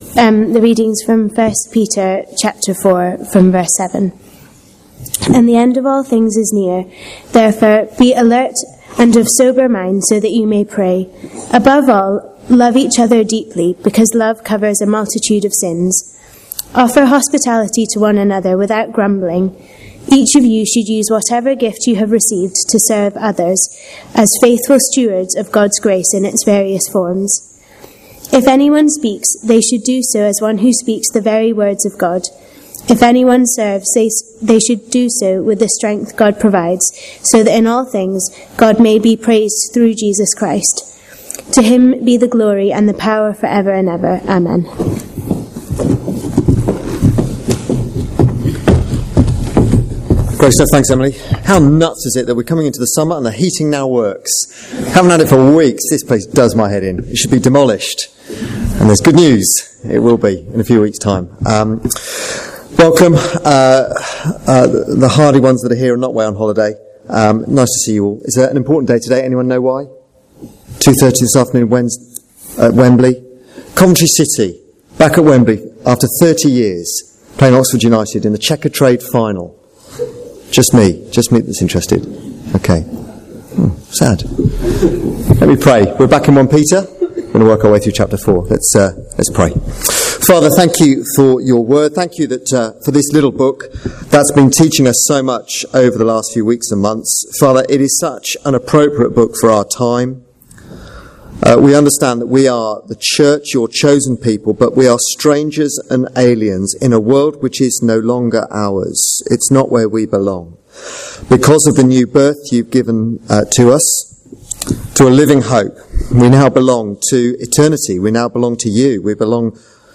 Sermon Video